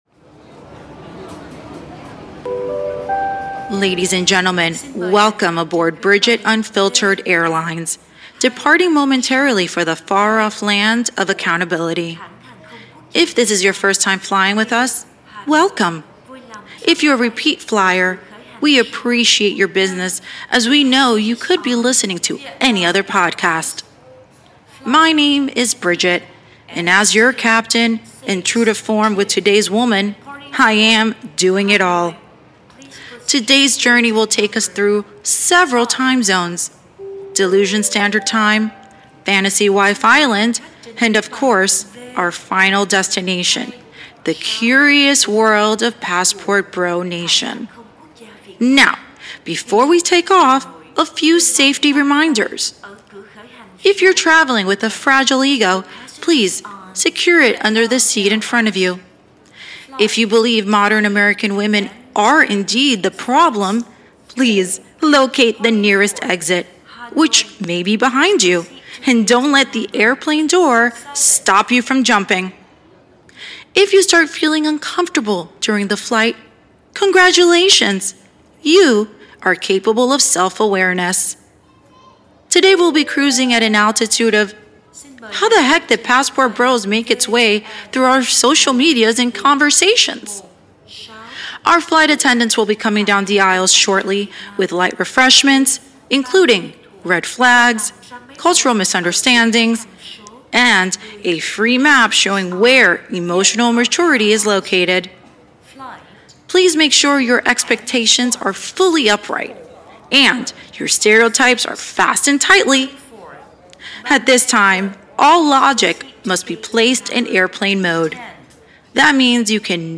Blending mid-century radio storytelling with modern day perspective